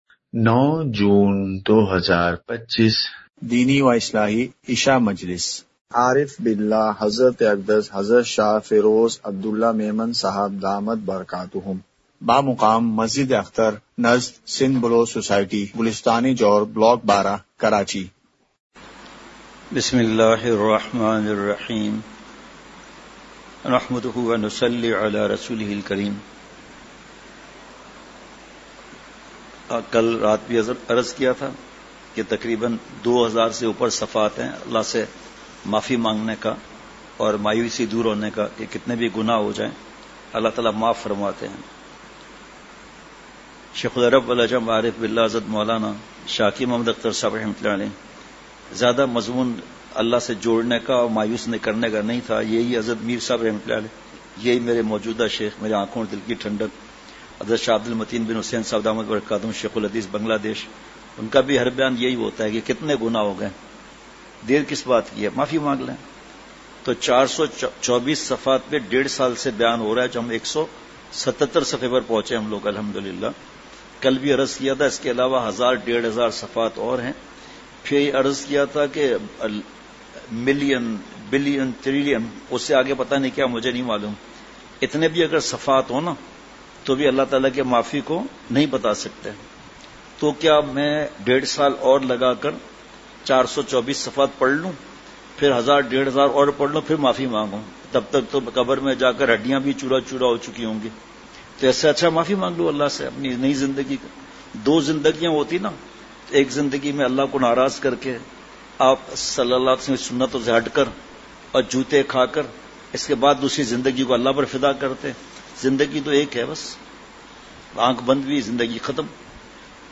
اصلاحی مجلس کی جھلکیاں مقام:مسجدِ اختر نزد سندھ بلوچ سوسائٹی گلستانِ جوہر کراچی